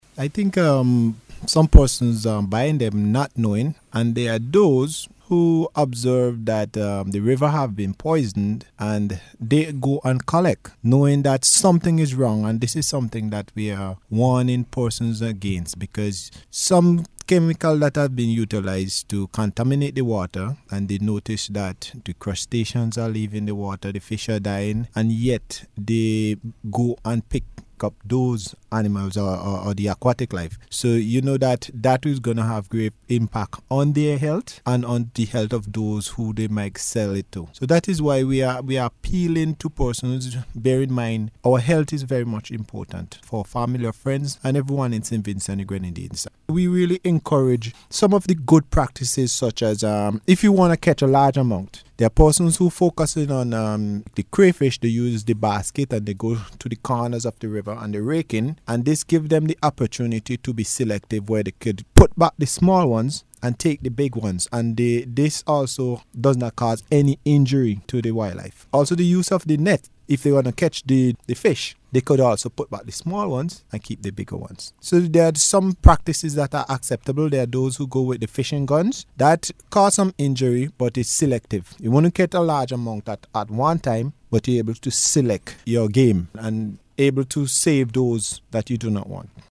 during the Face to Face programme aired on NBC Radio on Monday.